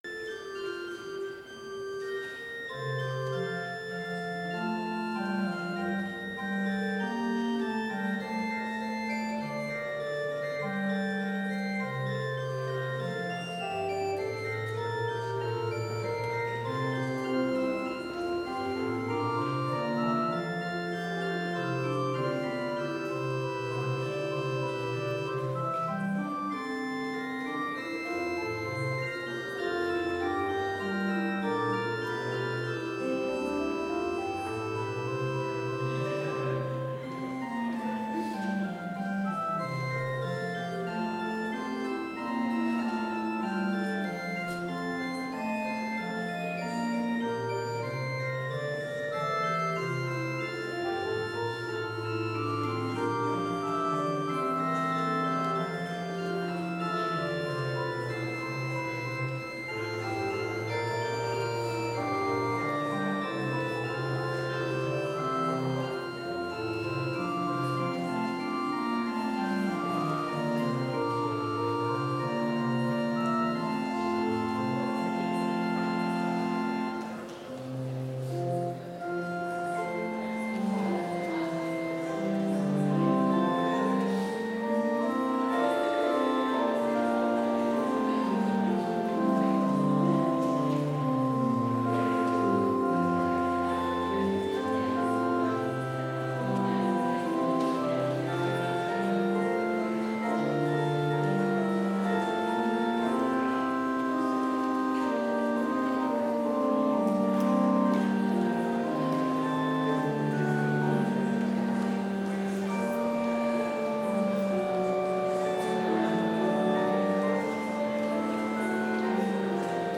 Complete service audio for Chapel - December 4, 2019